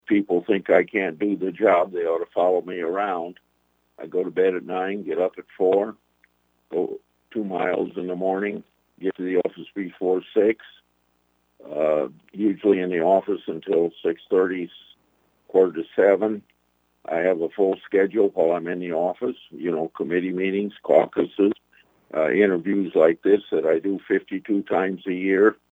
Grassley made his comments on October 18th during an interview with Iowa radio reporters.